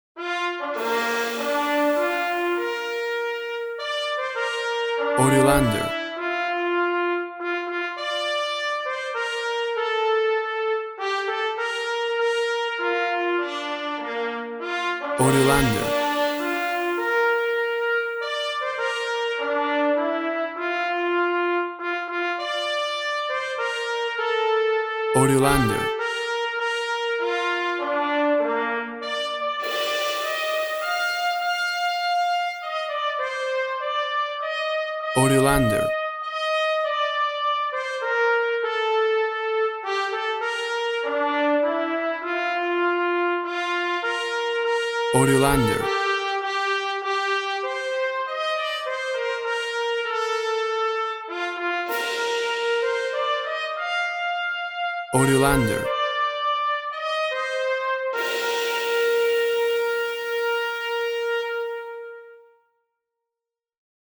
A vibrant and heartwarming traditional version
classical orchestration including , brass and percussion
Tempo (BPM) 100